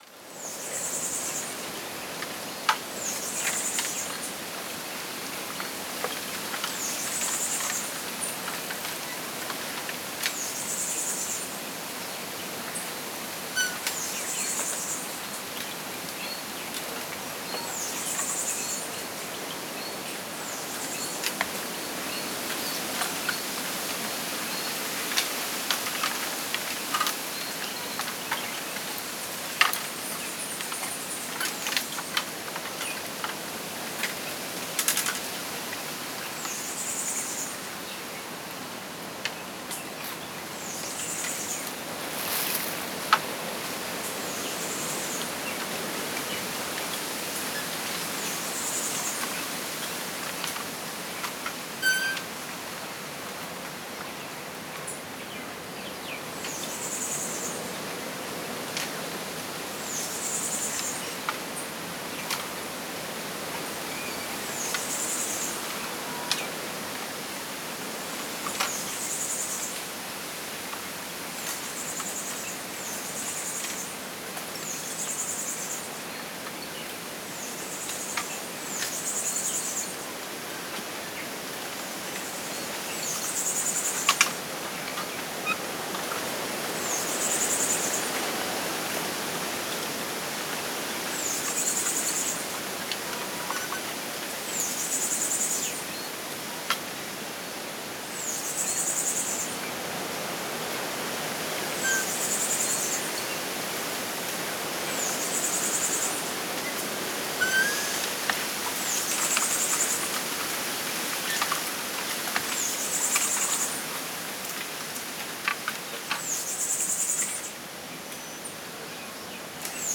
High_bamboo.R.wav